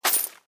ui_interface_31.wav